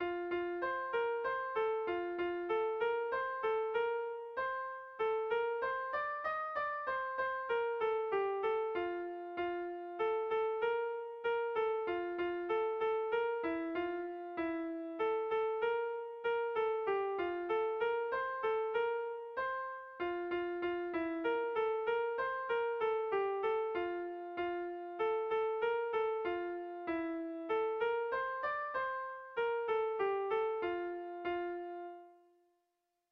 Bertso melodies - View details   To know more about this section
ABD1D2B2B